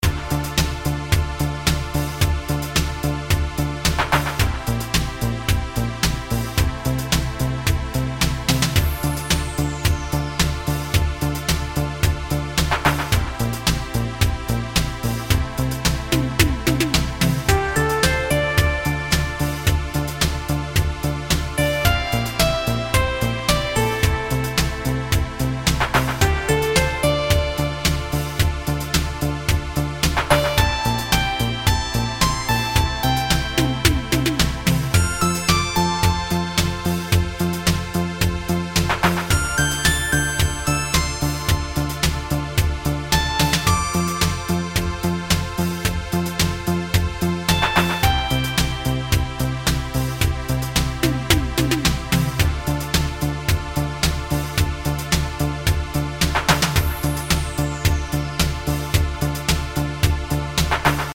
A Valerie Dore-inpsired Italo track. Yamaha DX 7, Solina and Simmons Drums